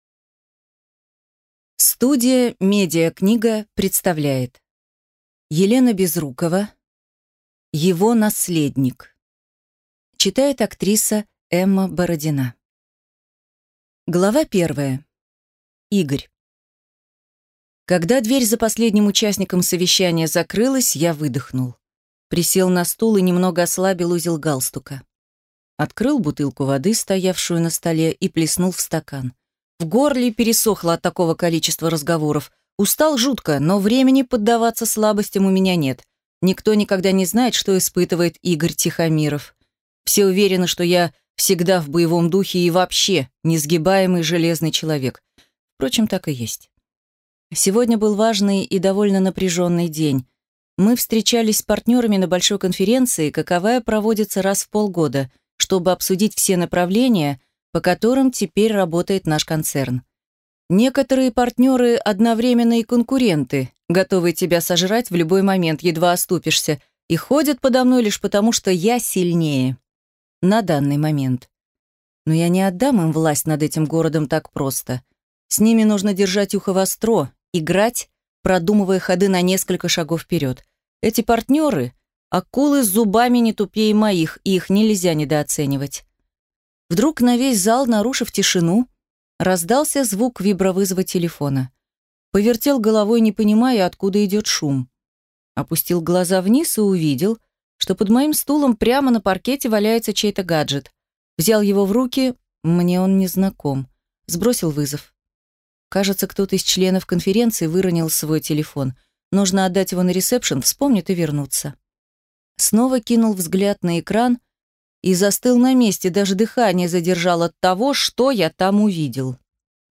Аудиокнига Его наследник | Библиотека аудиокниг